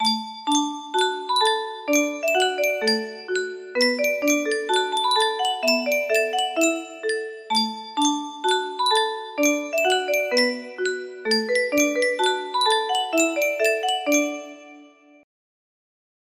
Yunsheng Music Box - Unknown Tune Y934 music box melody
Full range 60